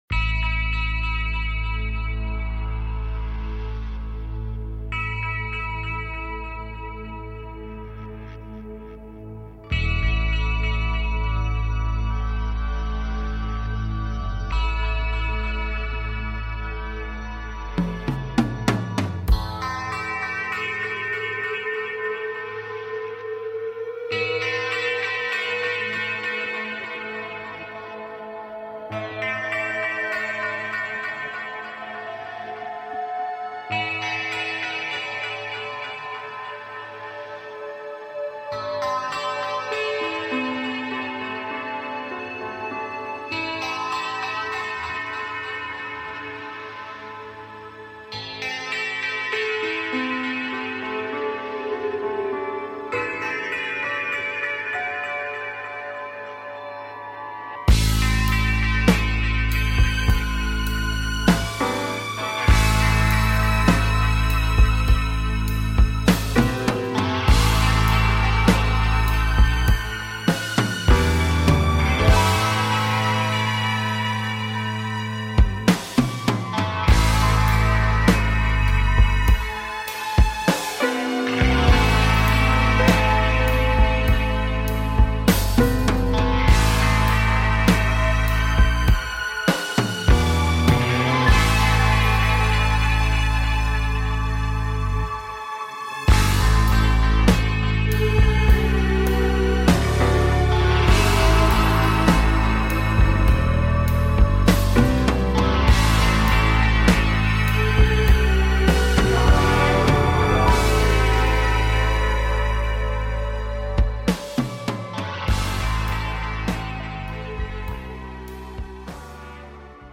Show on Homesteading and taking caller questions